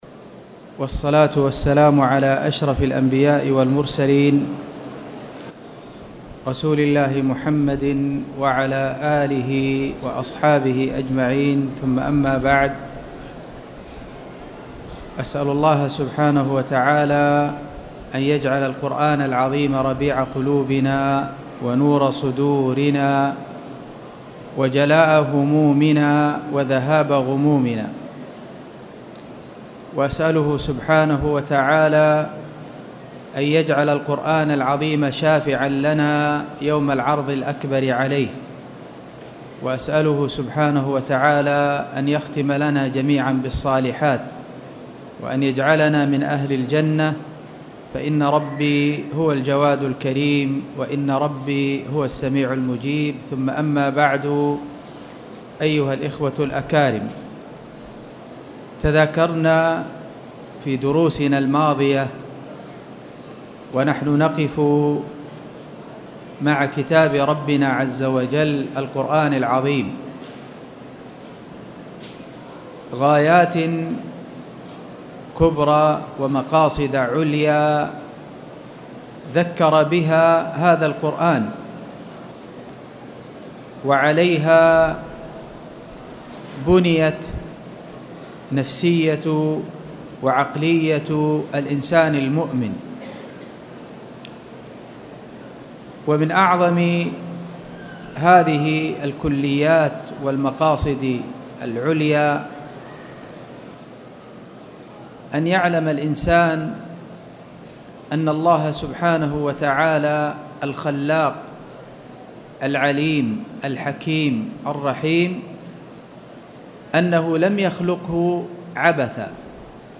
تاريخ النشر ٧ صفر ١٤٣٨ هـ المكان: المسجد الحرام الشيخ